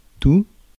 tout, thé, tchèque sty